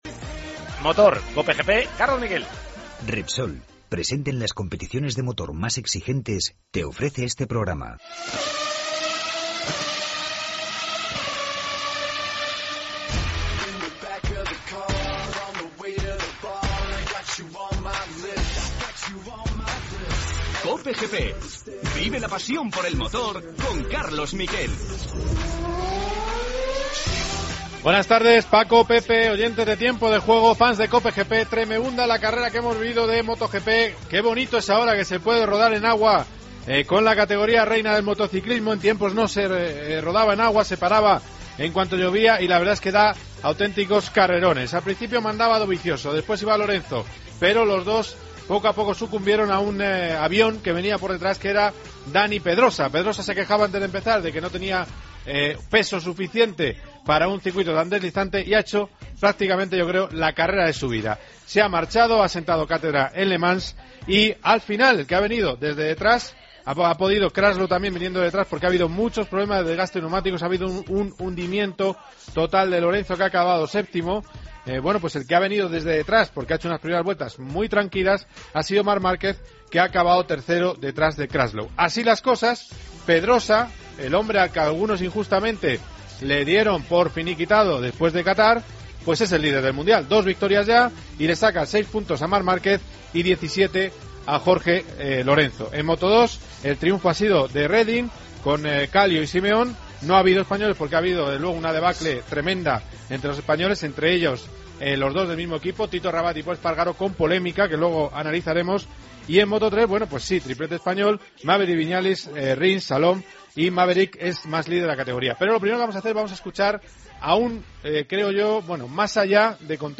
AUDIO: Te traemos toda al información del Gran Premio de Francia de moticiclismo, con la entrevista a Maverick Viñales, ganador de Moto 3....